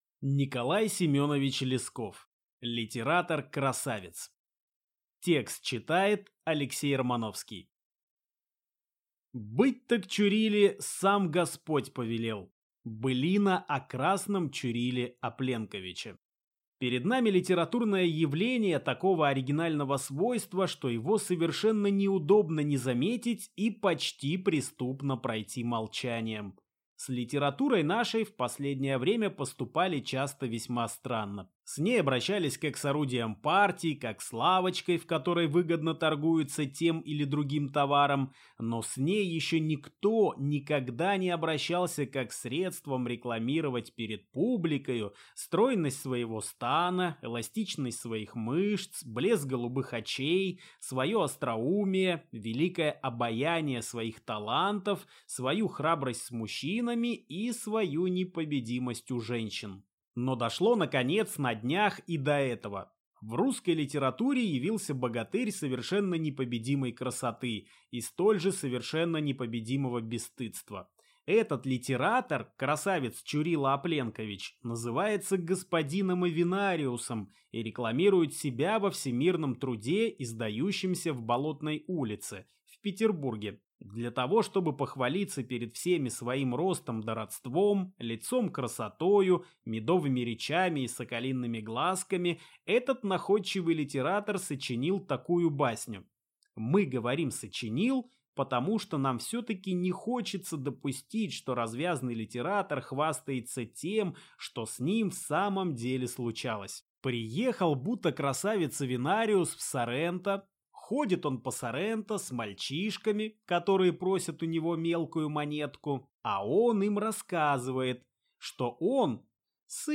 Аудиокнига Литератор-красавец | Библиотека аудиокниг